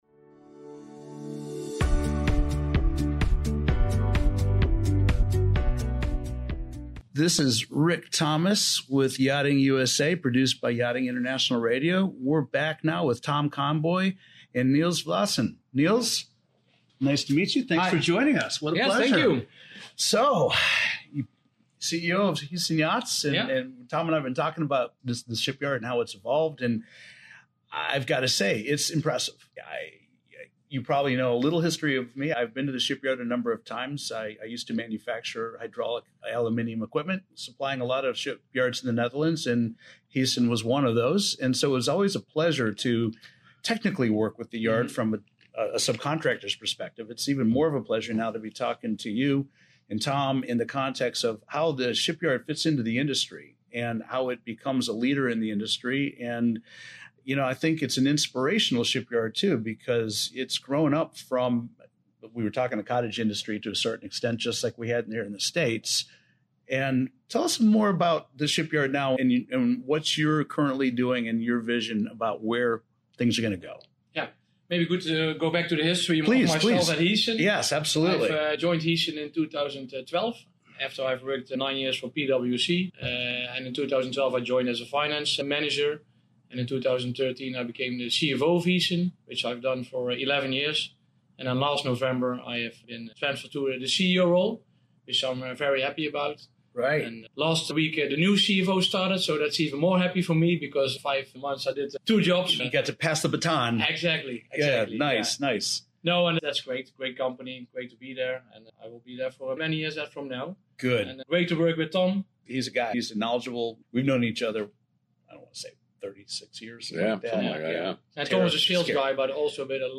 This discussion also delves into the challenges and opportunities within the U.S. yacht building industry.